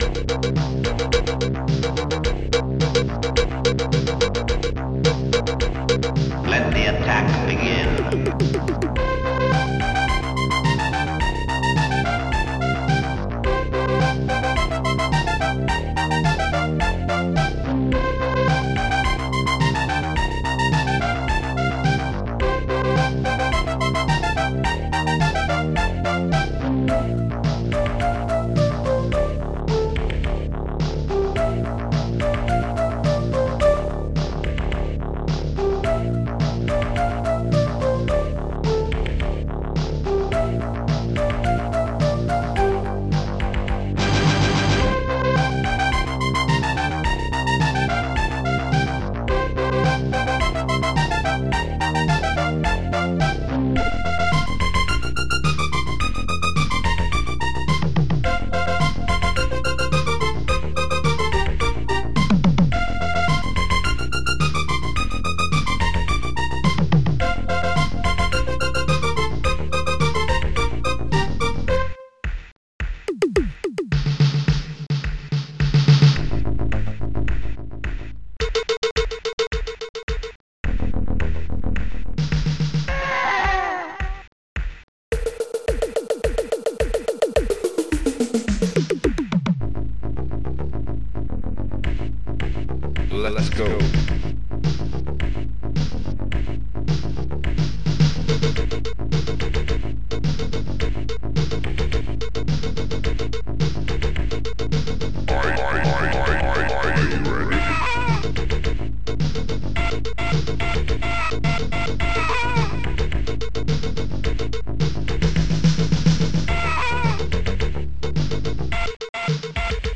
st-01:funbass
st-01:hallbrass
st-03:shakuhachi
st-03:orch.hit